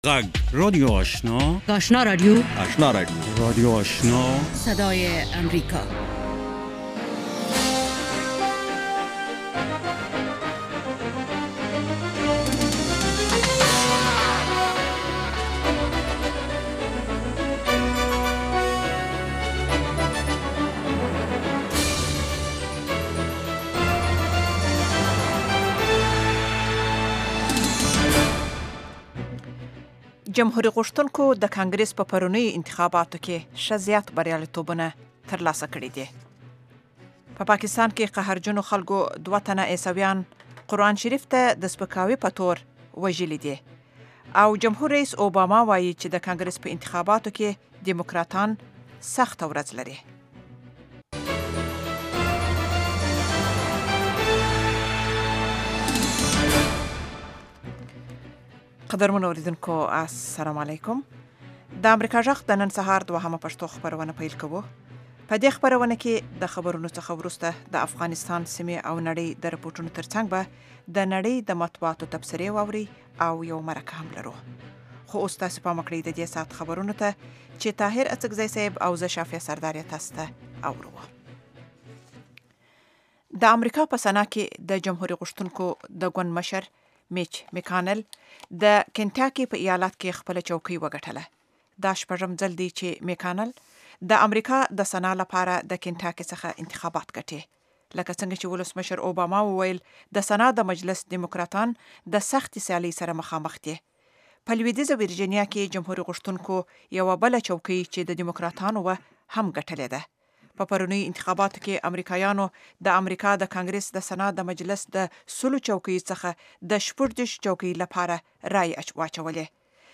دویمه سهارنۍ خبري خپرونه
په دې نیم ساعته خپرونه کې د افغانستان او نورې نړۍ له تازه خبرونو وروسته مهم رپوټونه او مرکې اورېدای شئ.